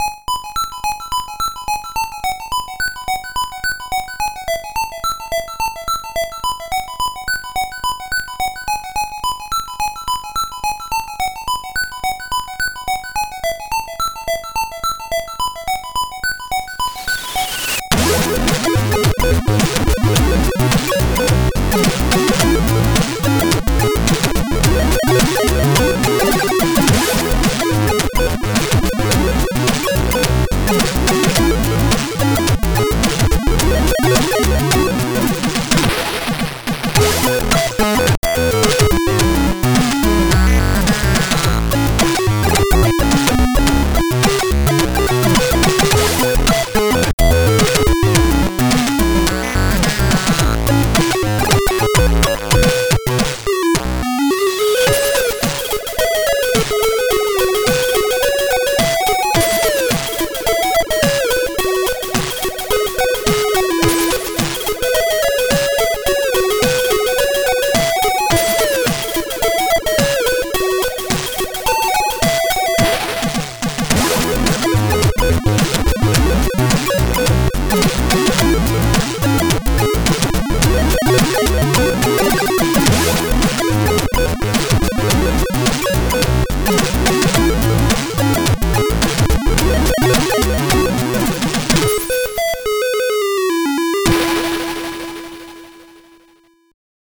• AY-music for ZX-Spectrum for limited time
ZX Spectrum + AY
• Sound chip AY-3-8912 / YM2149